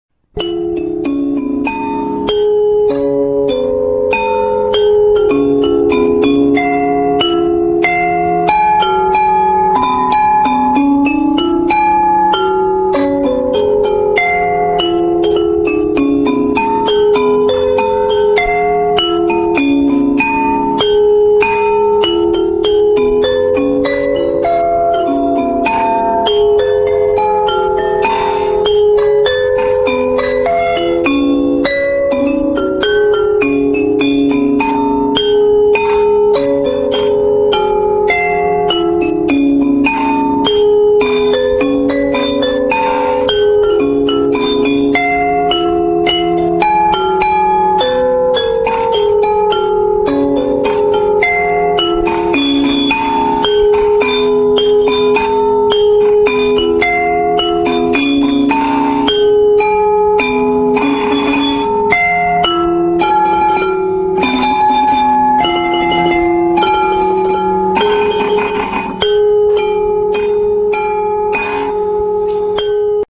Ranad Thum Lek
The keys are made in the same shape as in the ranad ek lek but are larger, thus giving deeper tones comparable to the ranad thum.